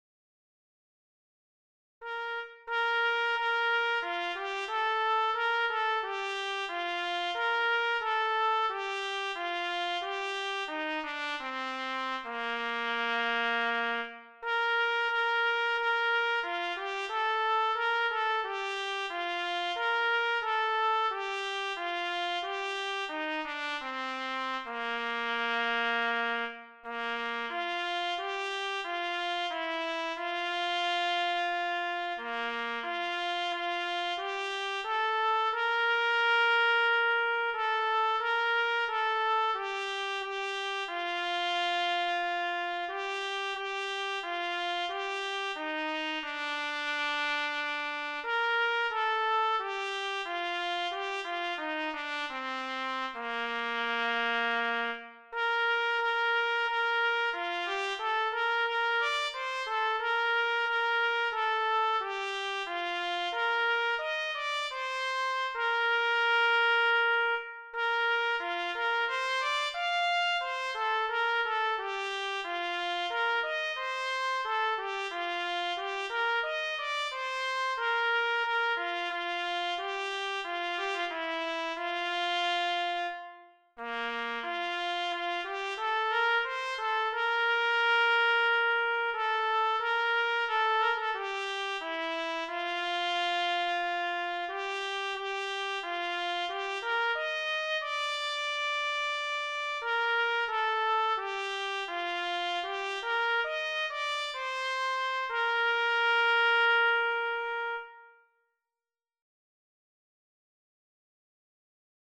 DIGITAL SHEET MUSIC - TRUMPET SOLO
Sacred Music, Hymns, Unaccompanied Solo